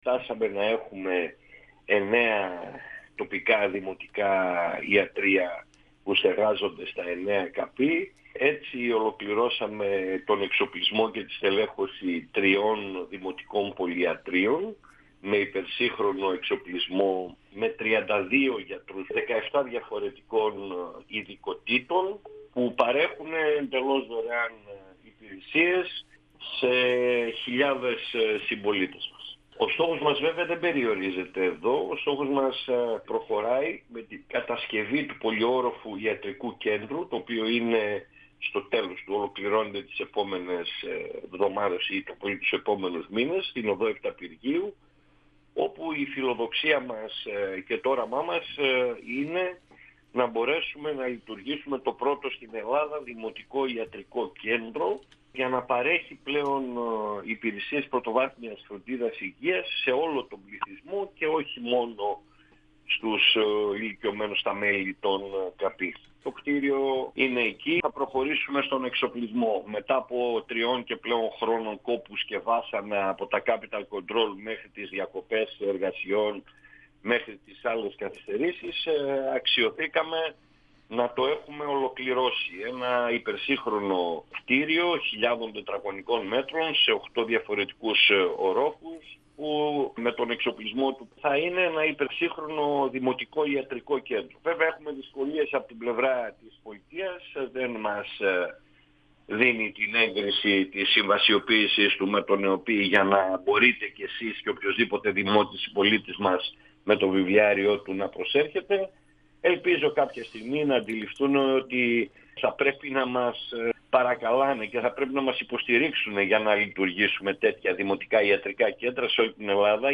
Ο δήμαρχος Νεάπολης – Συκεών Σίμος Δανιηλίδης, στον 102FM του Ρ.Σ.Μ. της ΕΡΤ3
Συνέντευξη